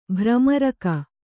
♪ bhramaraka